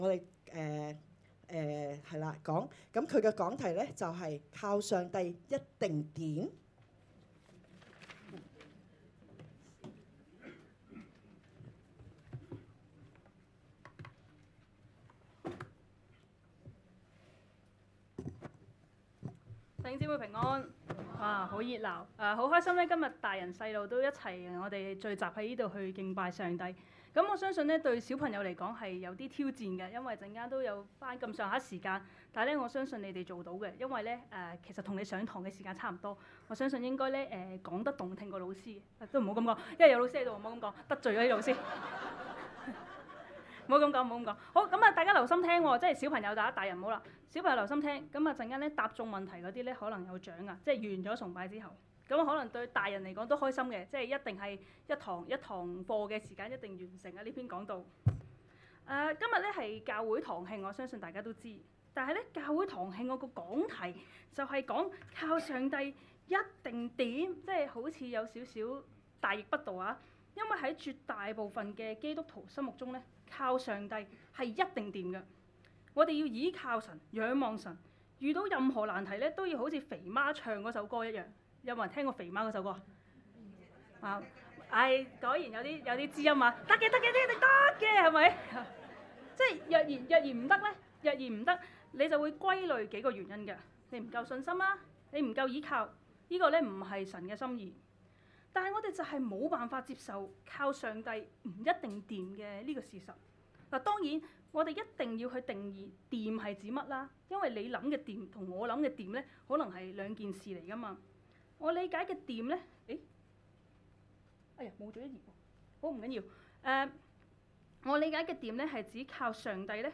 2026年2月8日第卅七週年堂慶合堂崇拜